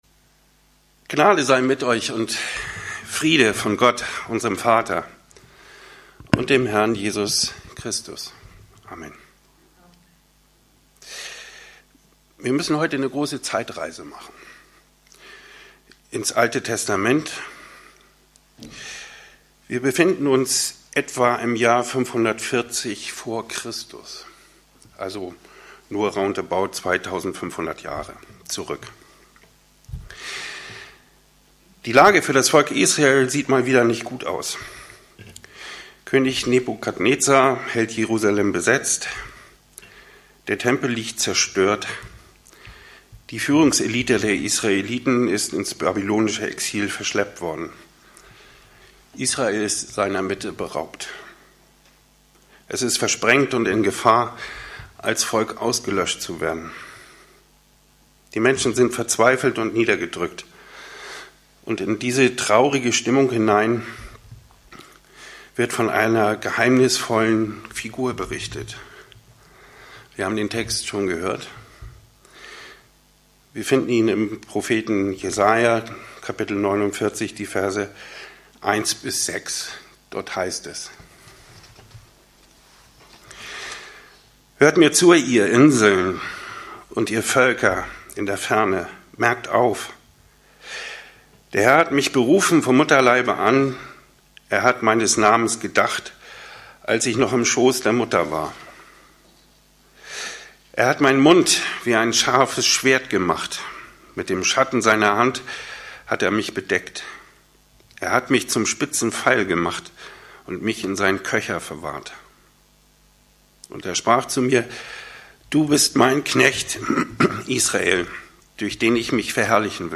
Predigt vom 16. Dezember 2018